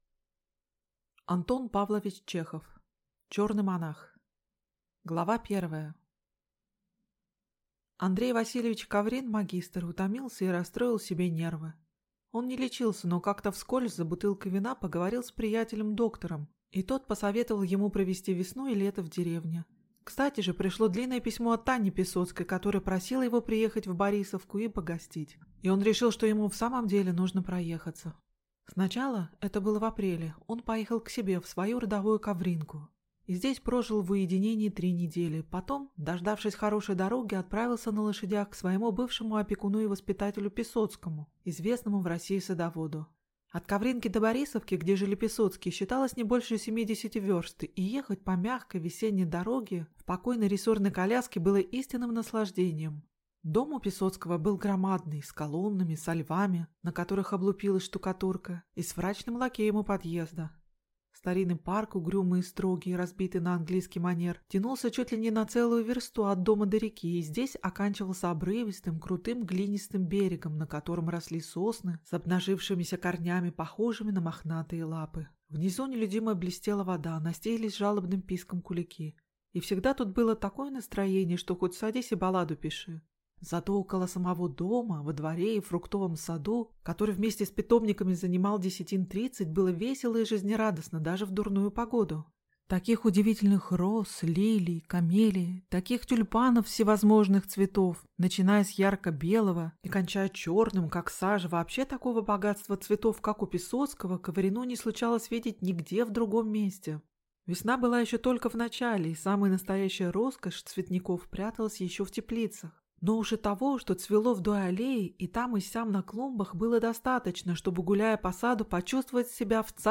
Аудиокнига Чёрный монах | Библиотека аудиокниг